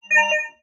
Hi Tech Game System Alert.wav